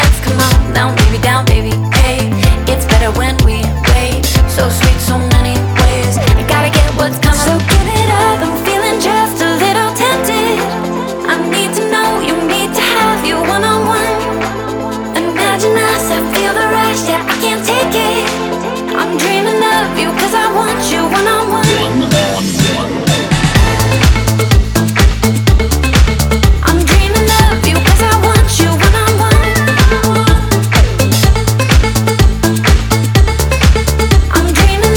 Жанр: Танцевальные
# Dance